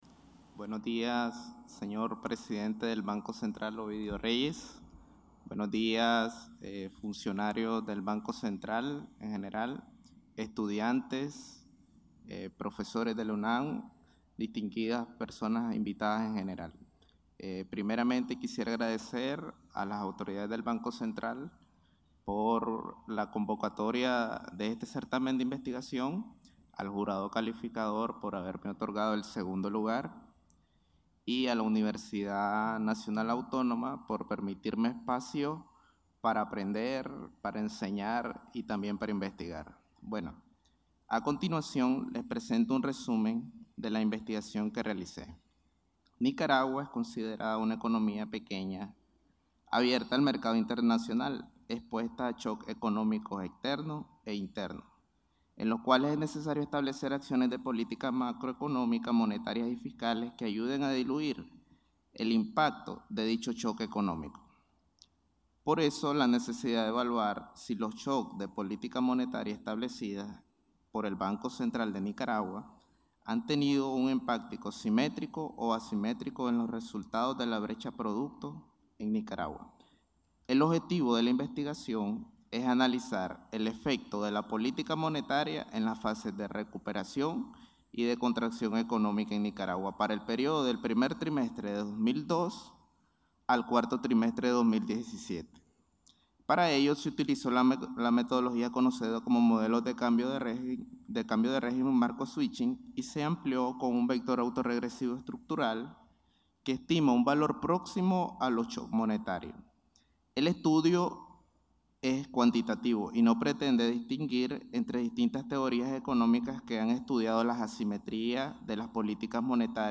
Audio ganador segundo lugar